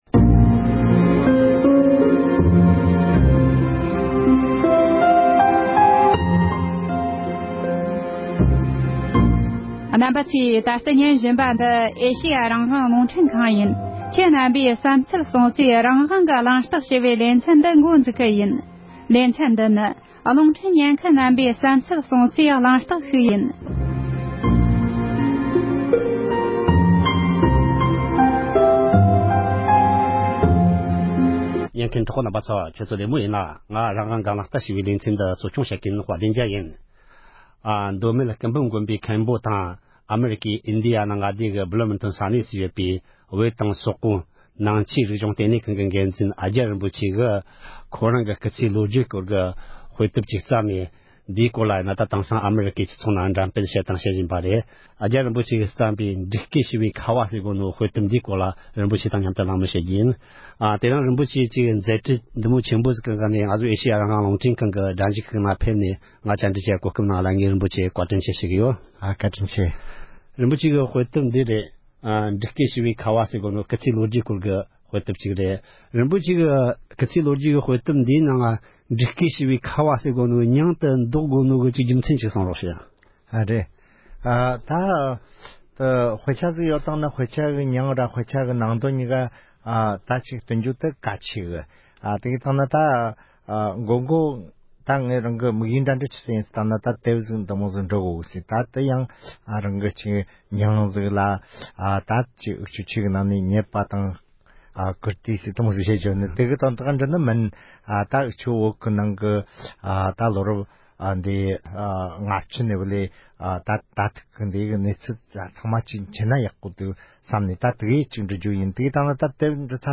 ཨ་ཀྱ་རིན་པོ་ཆེ་ཡིས་འབྲུག་སྐད་ཞི་བའི་ཁ་བ་ཞེས་པའི་རང་རྣམ་ཞིག་དབྱིན་ཡིག་ཏུ་པར་བསྐྲུན་ཡོད་པའི་སྐོར་གླེང་མོལ།